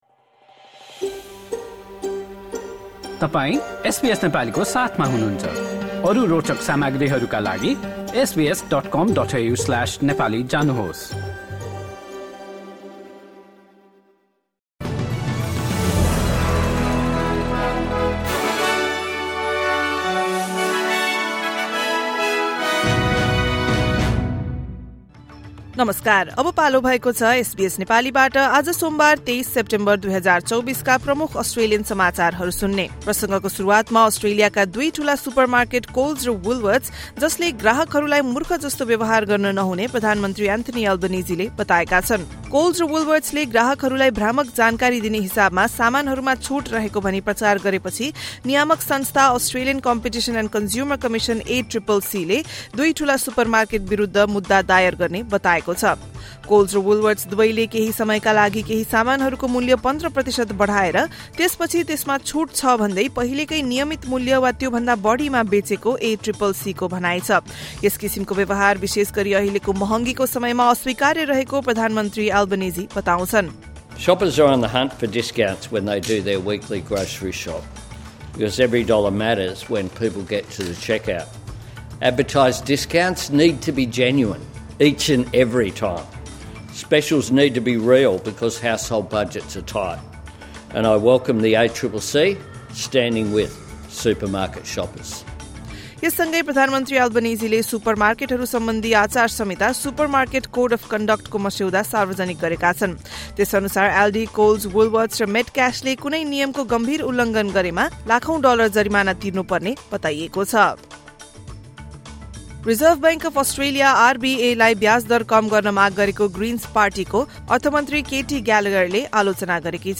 SBS Nepali Australian News Headlines: Monday, 23 September 2024